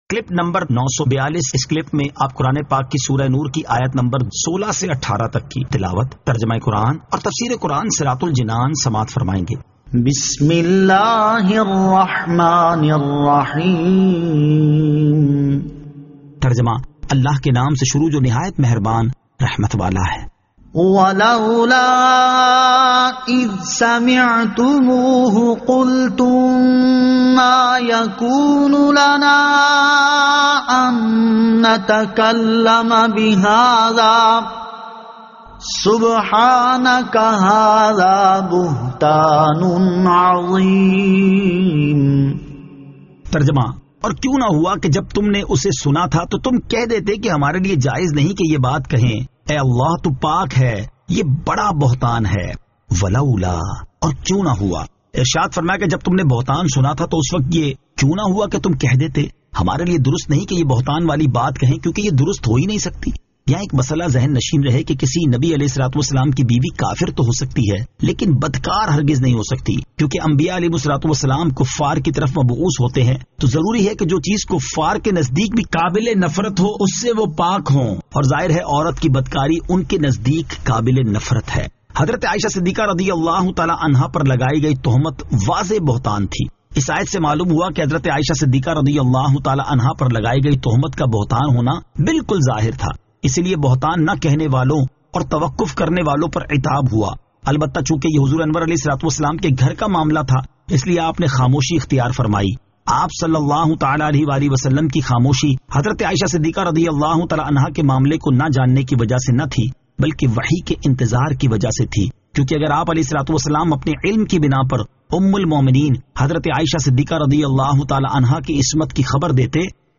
Surah An-Nur 16 To 18 Tilawat , Tarjama , Tafseer